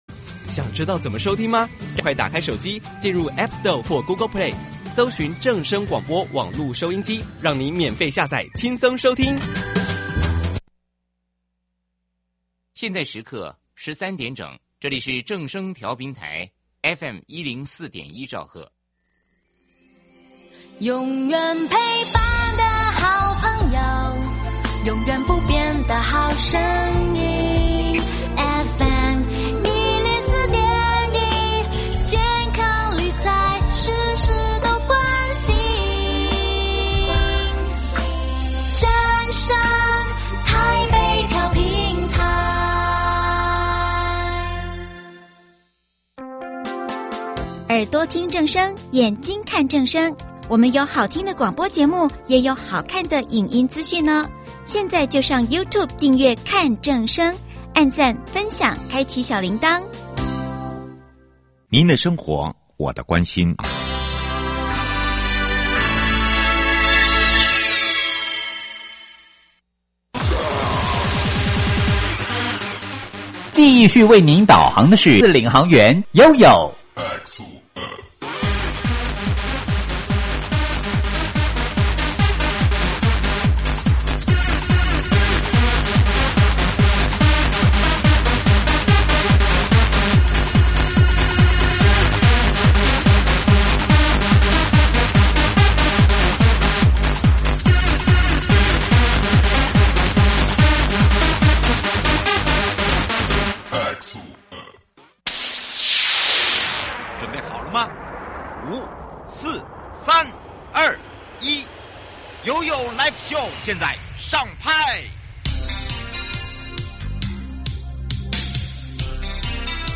受訪者： 1.國土署都市基礎工程組 2.彰化縣政府交通處林孟弘處長